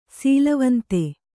♪ sīlavante